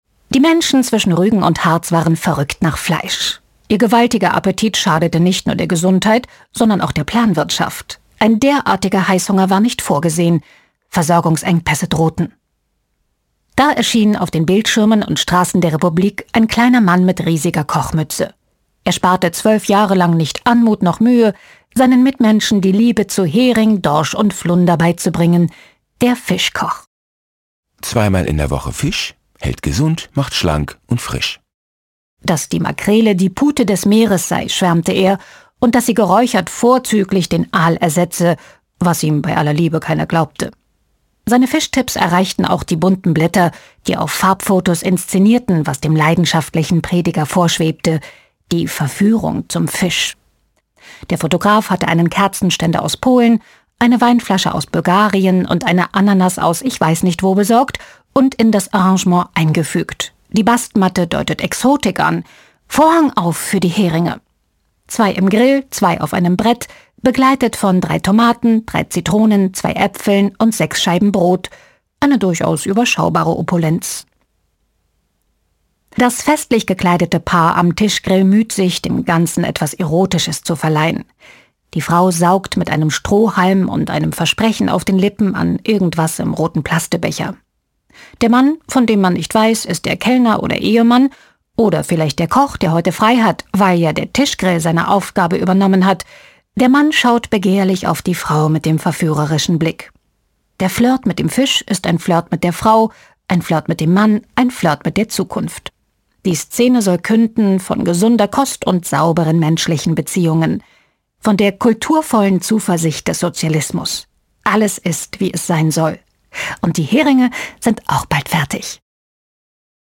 Beispiel einer Hörstation